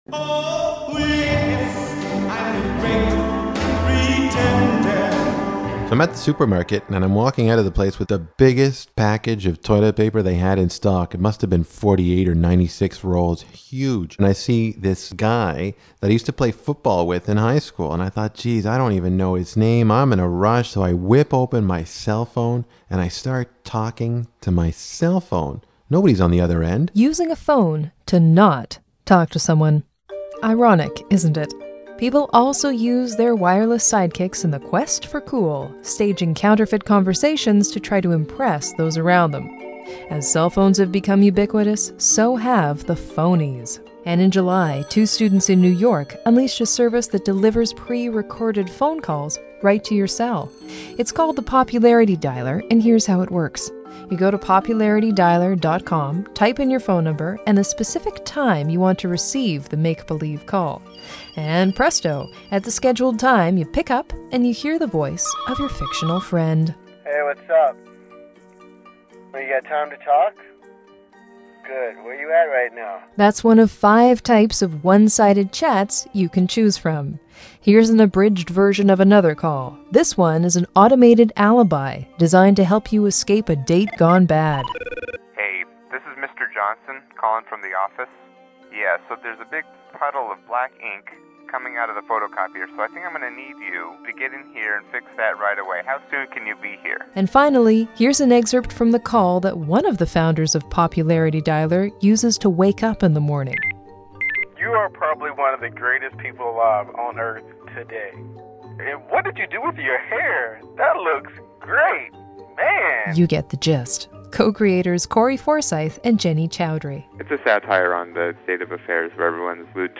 CBC radio interview